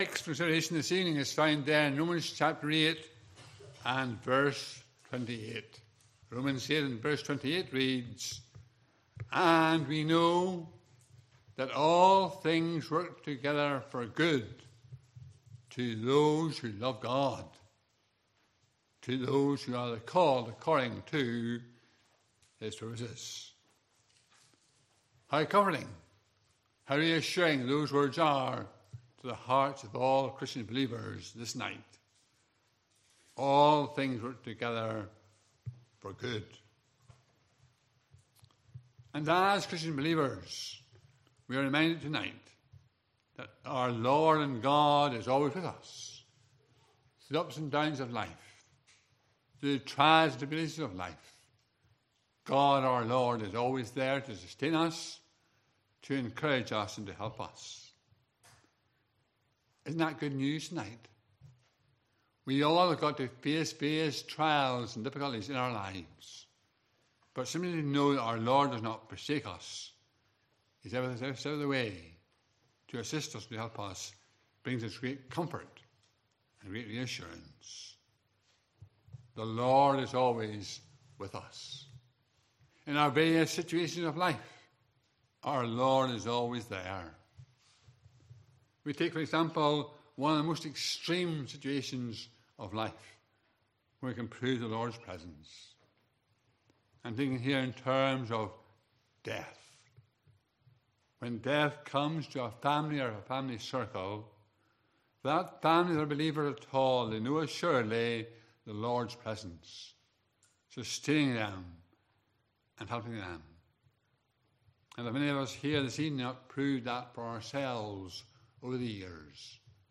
Passage: Romans 8:28 Service Type: Evening Service